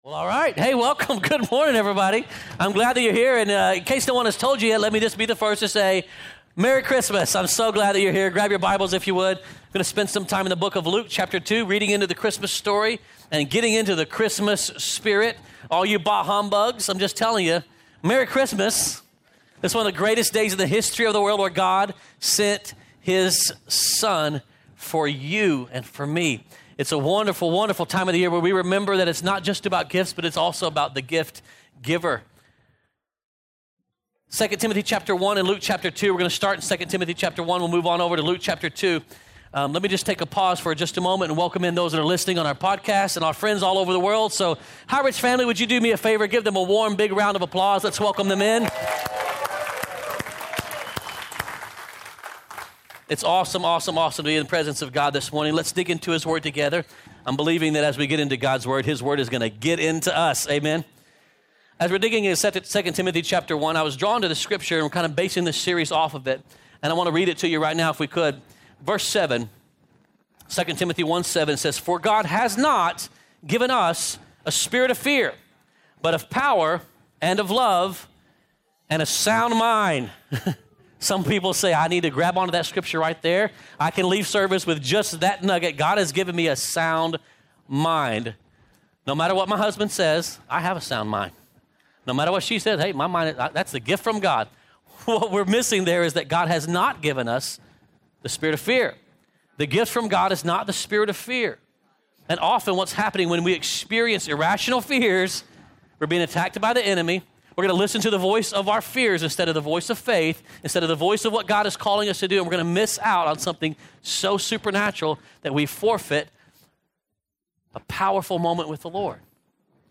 2018 Sermon